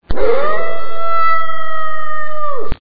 Animal Sound Effects
The sound bytes heard on this page have quirks and are low quality.
WEREWOLF HOWL # 2 2.61